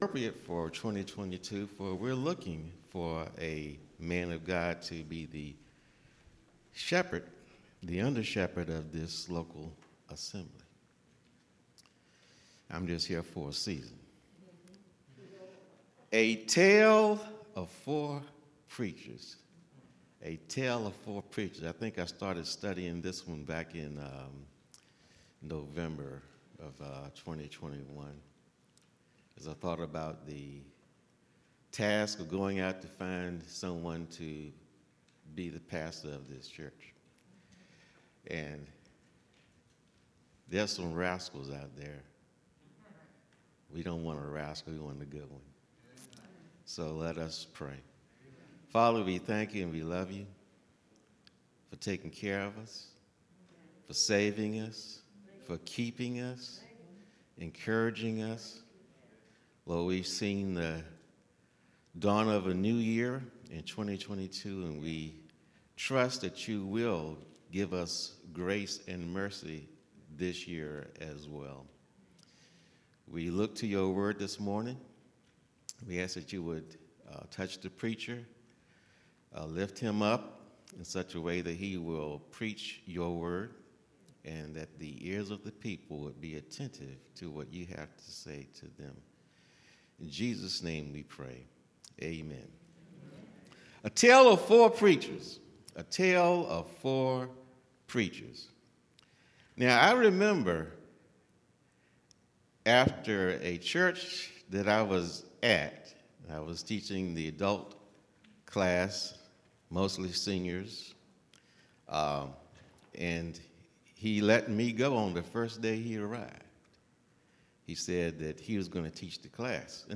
Visit Pulpit Points , our archive of more preached messages from the pulpit of New Berean Baptist Church